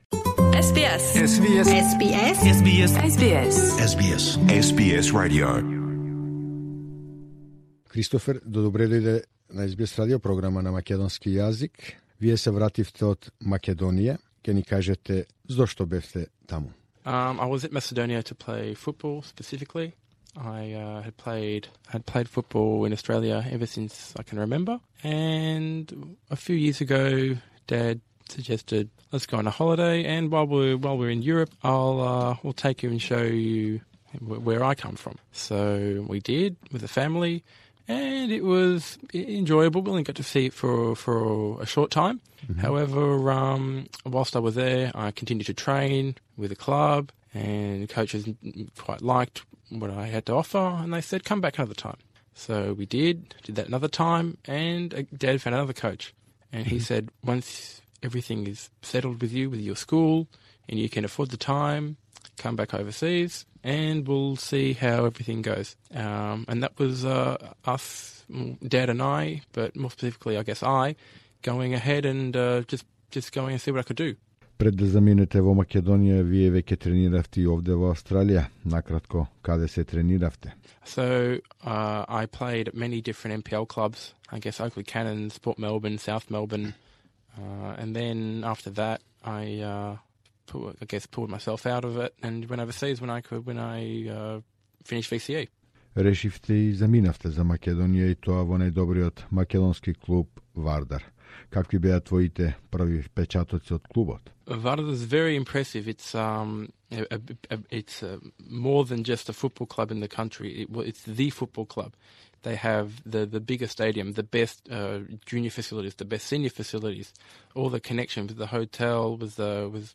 In an interview for SBS Radio’s Macedonian Program upon his return to Australia